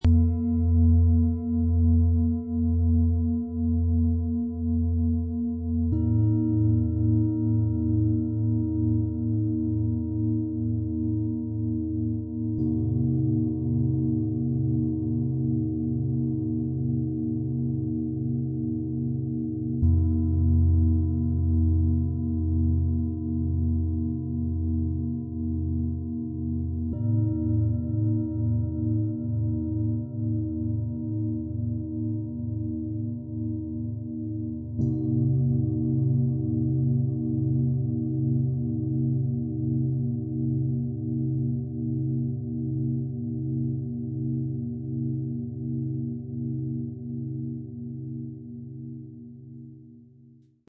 Erde fühlen. Mitte finden. Ruhe spüren - XXXL Klangmassage-Set aus 3 Klangschalen, Ø 21,2 - 30,2 cm, 5,68 kg
Tief, klar, zentrierend - Wie wirkt das Set?
Die größte Schale trägt einen tiefen, vollen Ton.
Sie füllen Räume mit einem tragenden, warmen Klang.
So entsteht ein Klang, der warm, voll und klar trägt.
Das hörbare Pulsieren wirkt in der Aufnahme stärker, als Sie es live erleben.